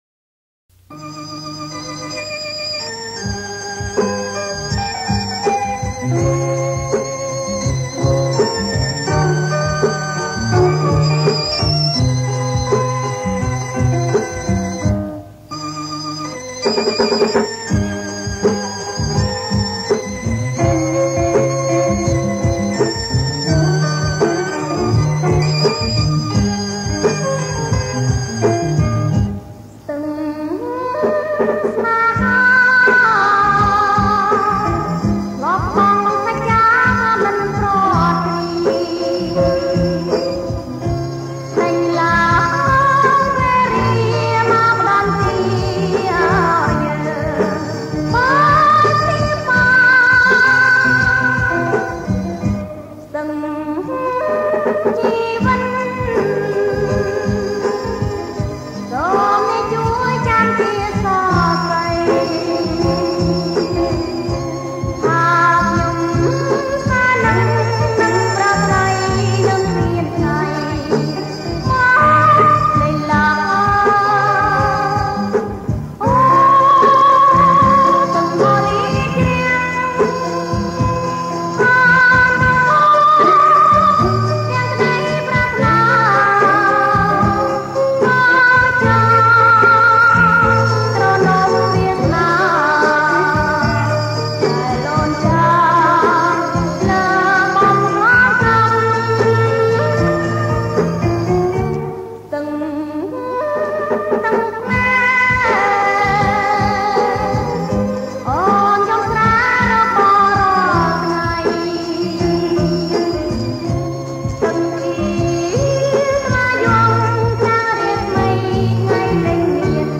• ប្រគំជាចង្វាក់ Bolero Surf